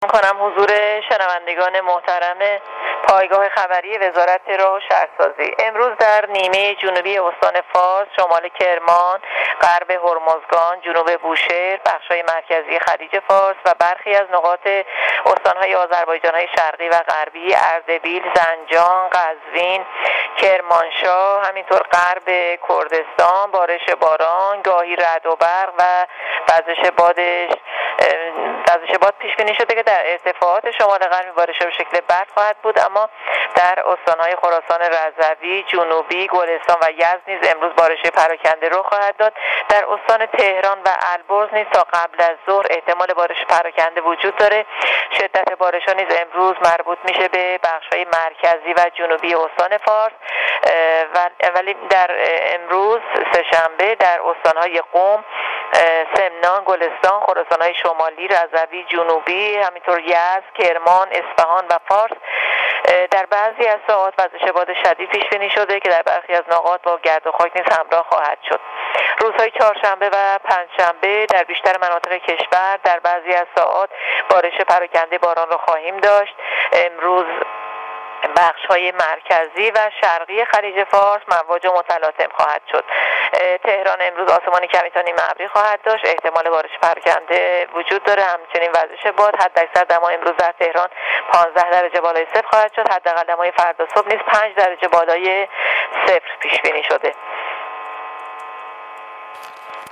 رادیو اینترنتی پایگاه خبری وزارت راه و شهرسازی گزارش می‌دهد؛